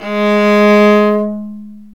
Index of /90_sSampleCDs/Roland - String Master Series/STR_Viola Solo/STR_Vla3 Arco nv
STR VIOLA 05.wav